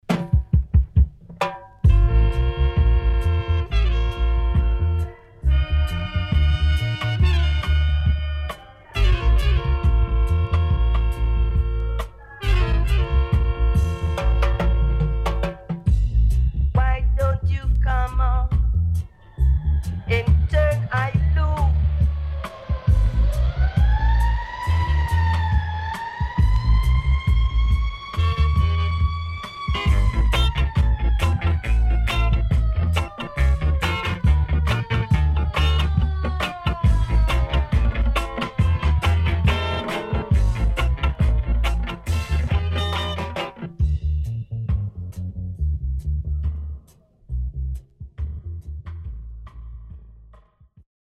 HOME > REISSUE [REGGAE / ROOTS]
緊張感漂うTop Killer Roots